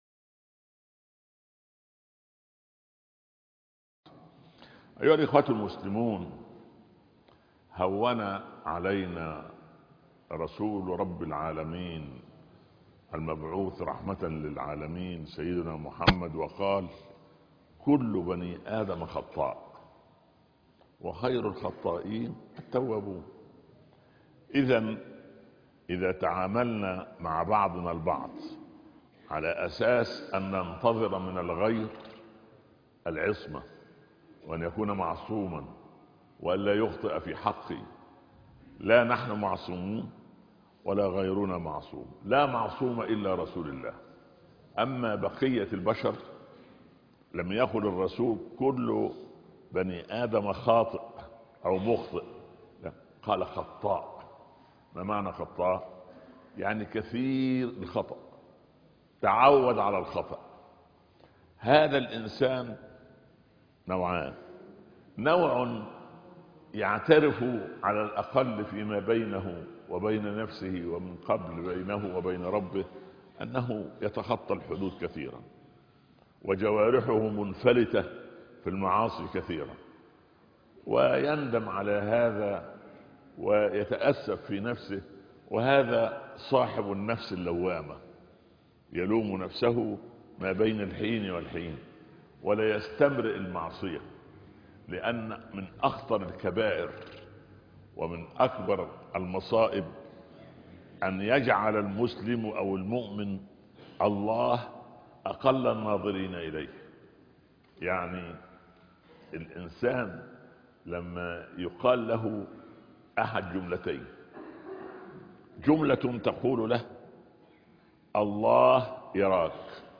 كيف تبدأ حياة جديدة؟ (خطبة الجمعة) - الشيخ عمر بن عبدالكافي